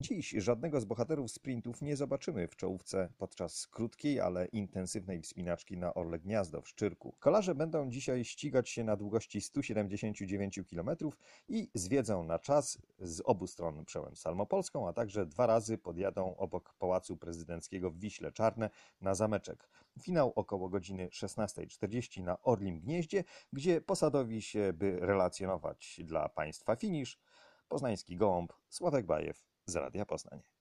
Więcej w relacji naszego wysłannika.